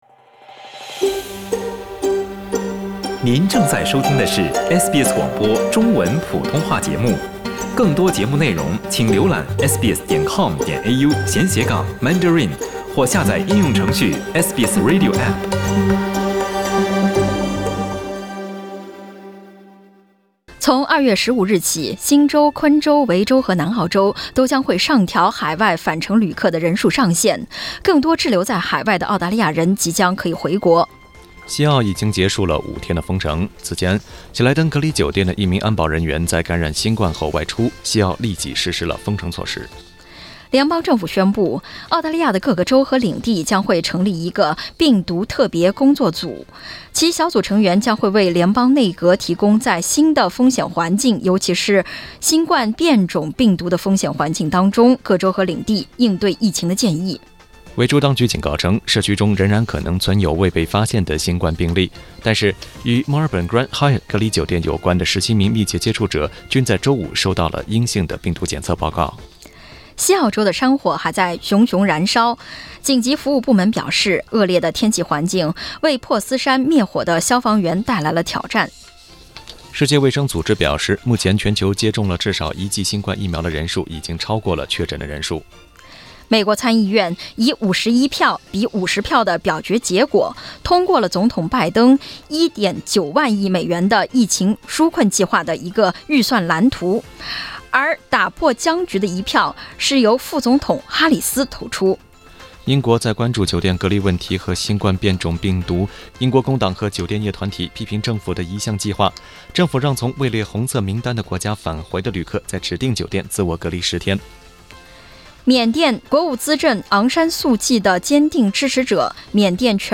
SBS早新闻（2月6日）
SBS Mandarin morning news Source: Getty Images